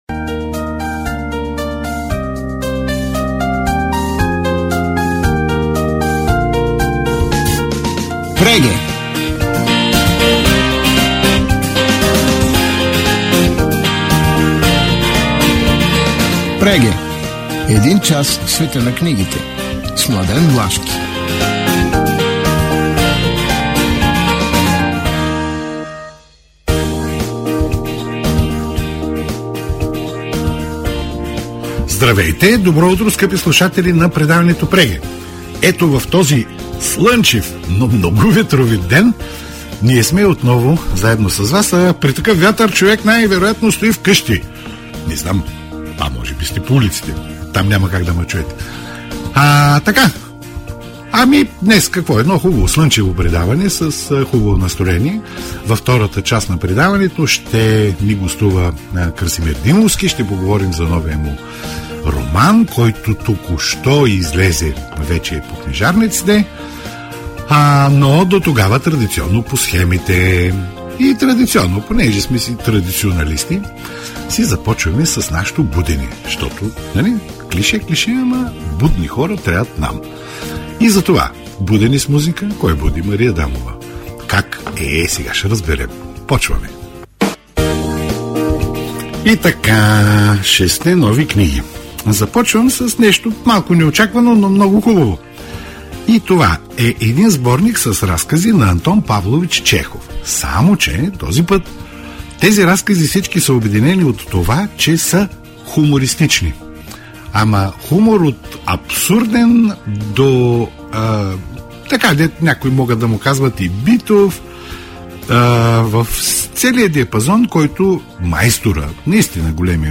В Преге – предаването за книги на Радио Пловдив, на 23 ноември 2024 бяха представени следните заглавия: